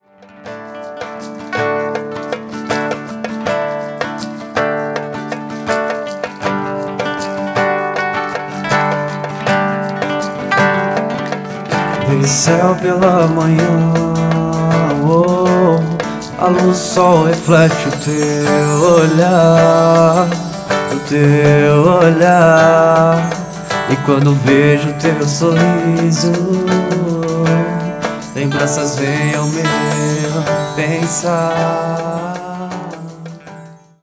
combina harmoniosamente timbres de vozes e talentos diversos
Violões
Bateria e Percussão